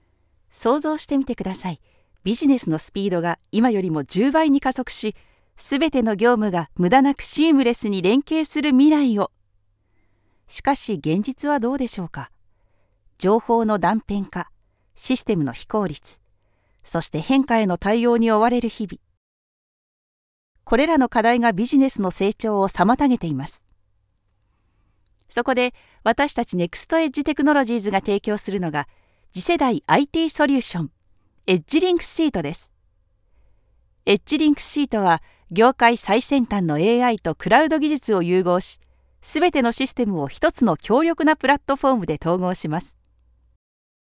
Natuurlijk, Veelzijdig, Vertrouwd, Warm, Zacht
Corporate
Explainer